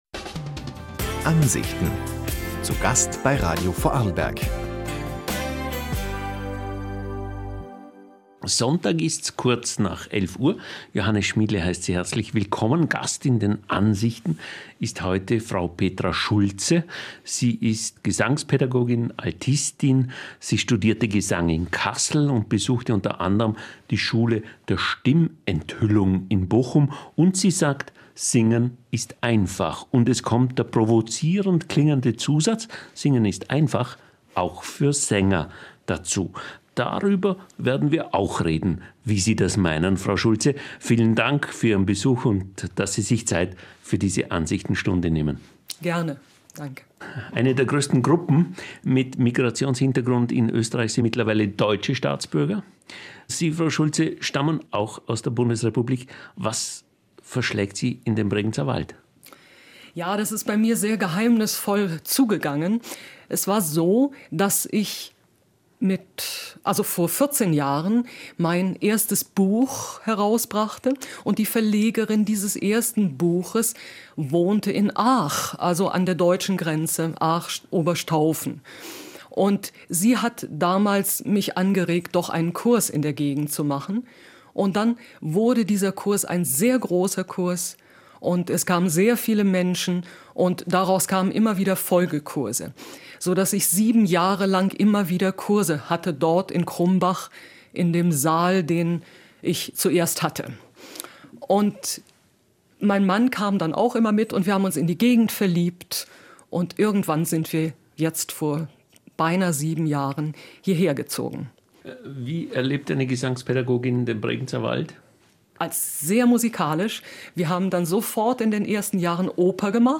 Radio Interview über Singen und Leben
Das folgende Interview wurde am 1. März 2015 ausgestrahlt auf ORF V (Radio Vorarlberg)